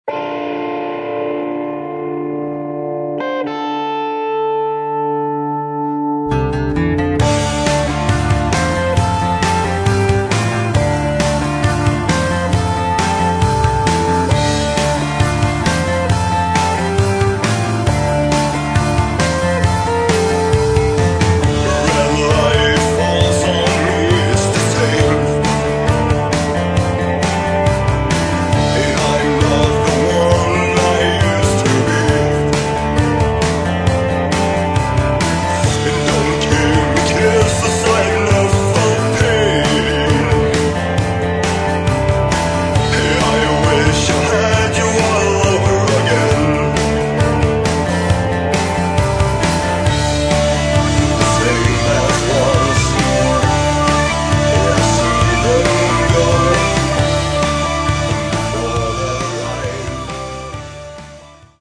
Второй полноформатный альбом шведской готической группы.
вокал, акустическая гитара
гитара
бас
клавиши
барабаны
гармоника
женский вокал